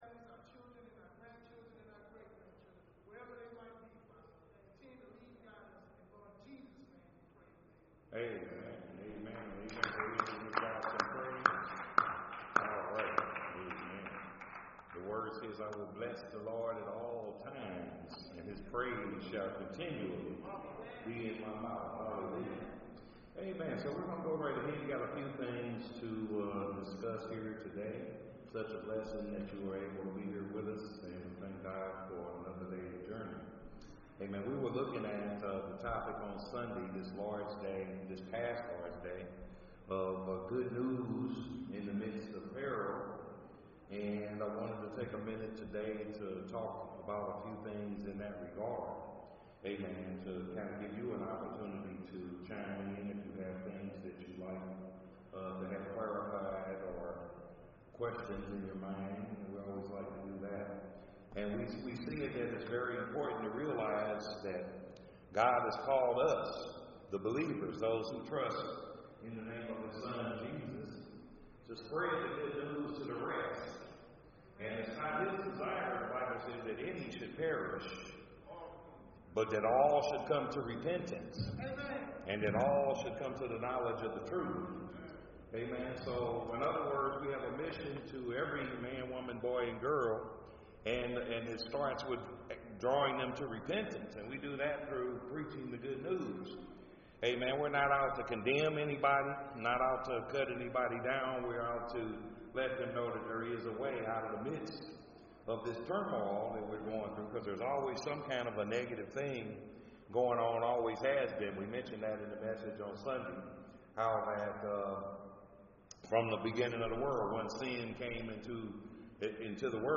This is a live Bible Study featuring questions and answers about sharing the Gospel of Jesus Christ as we go out into everyday life.